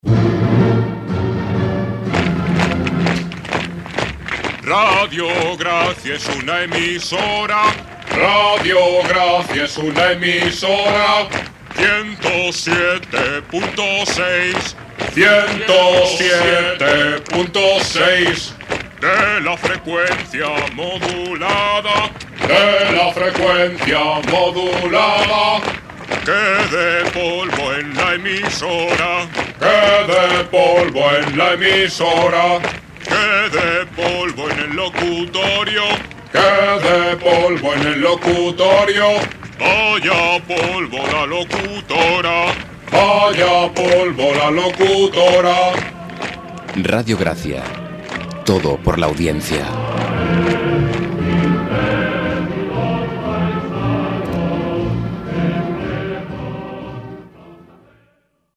Indicatiu de l'emissora i freqüència "todo por la audiencia".